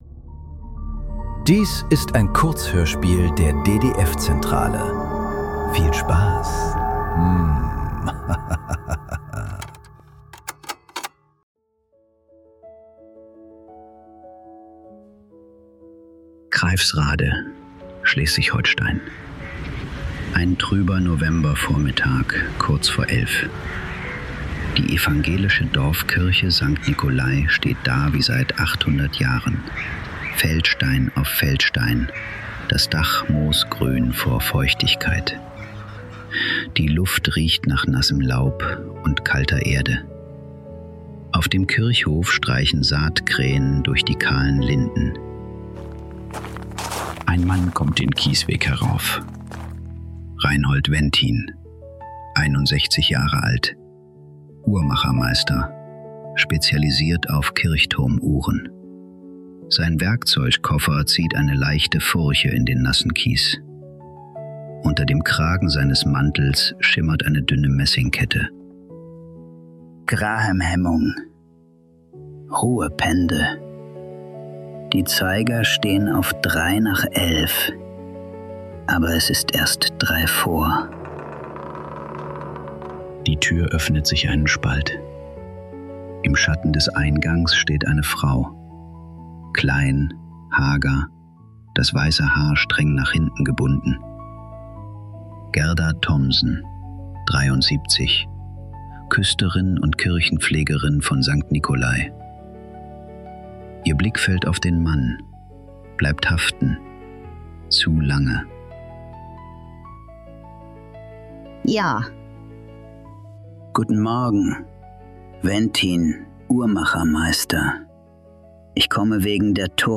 Drei Minuten vor der Welt ~ Nachklang. Kurzhörspiele. Leise.